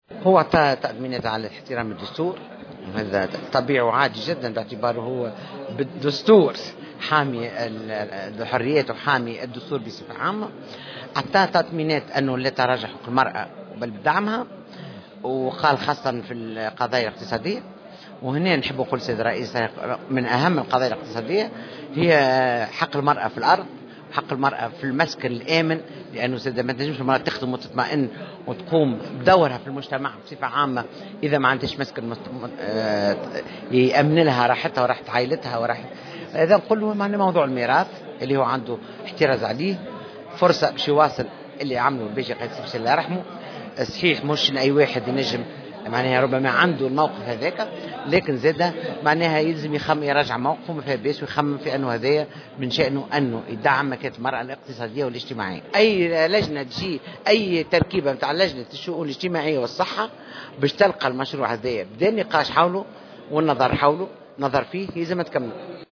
دعت النائب في البرلمان، بشرى بالحاج حميدة، في تصريح للجوهرة أف أم، رئيس الجمهورية الجديد قيس سعيد، إلى مواصلة ما بدأه الرئيس لراحل الباجي قائد السبسي، فيما يتعلق بقانون المساواة في الميراث.